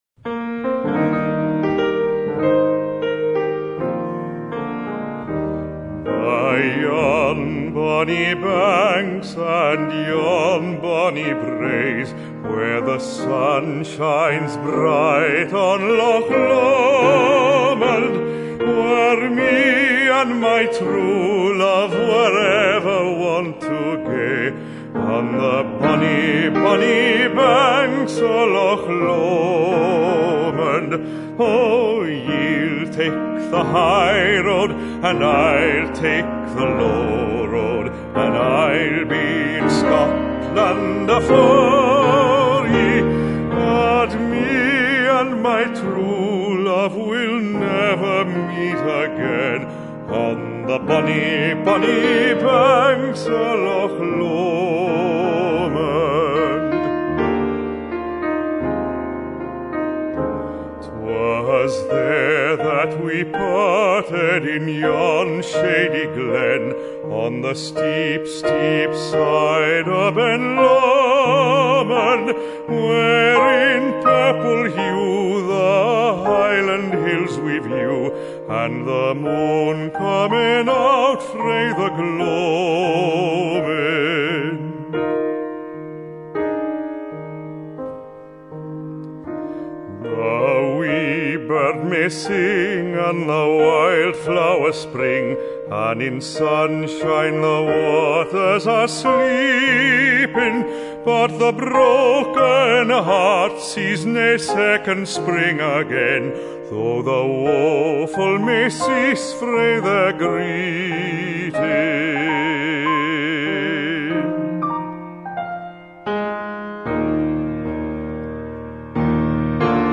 Voix Basse et Piano